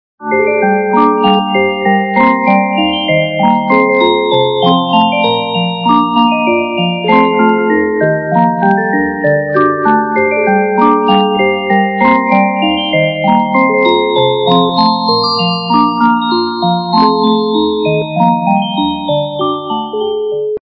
» Звуки » Будильники » Звонок - Будильник
При прослушивании Звонок - Будильник качество понижено и присутствуют гудки.